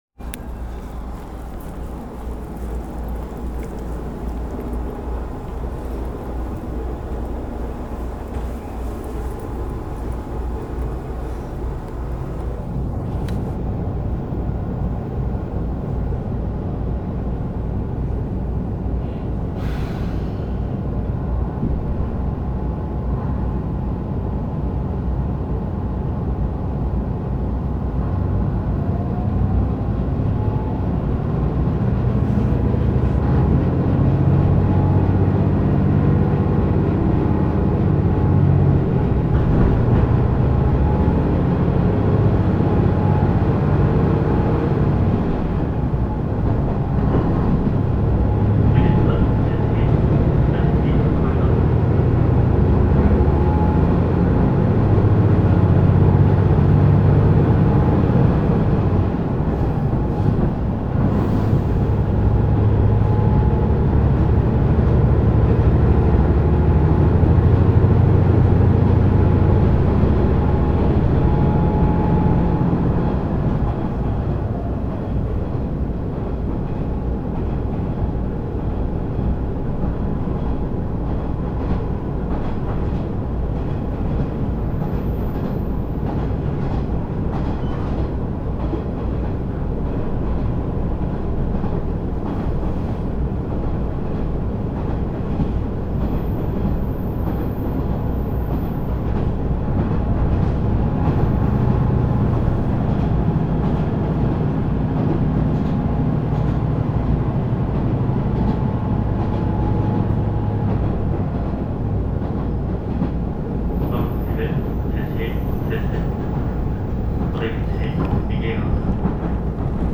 走行音
録音区間：佃～辻(お持ち帰り)